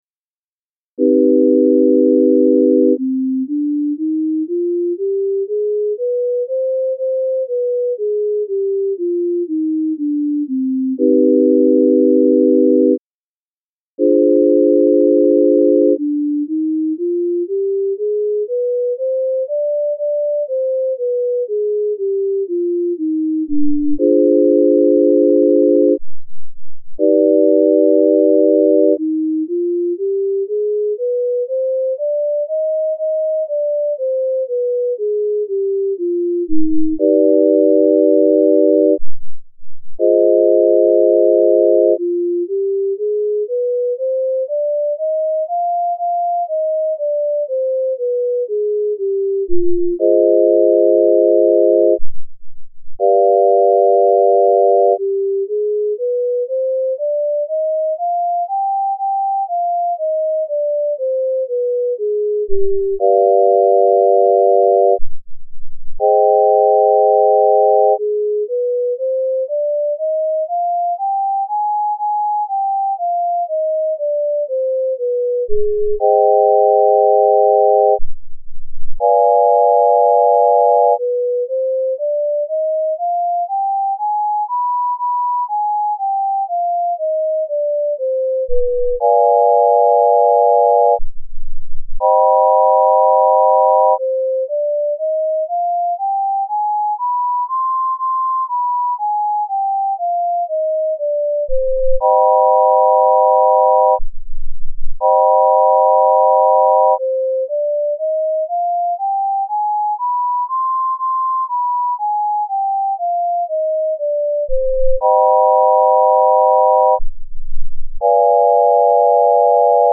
C-Harmonic Minor Scale Using the Just Scale